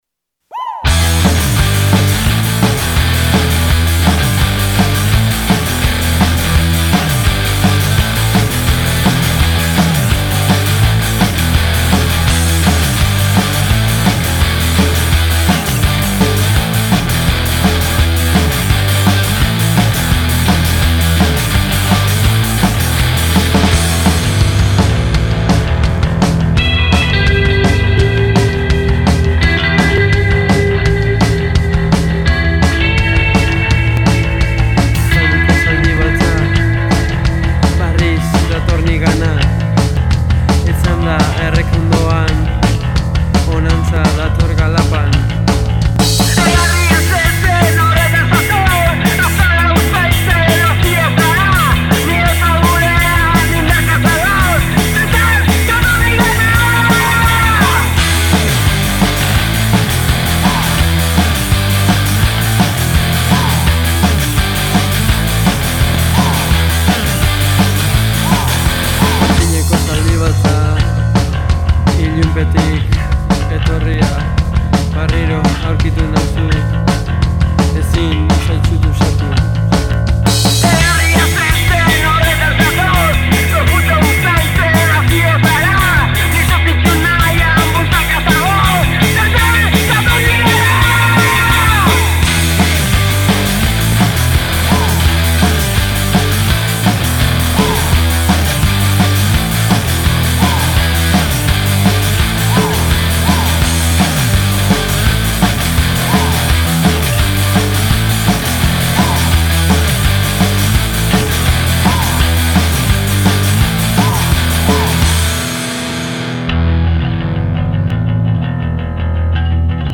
Bidaia psikodelikoa egin dugu
doinu dantzagarriekin hasi dugu
afrobeat doinuak ekarri dizkigu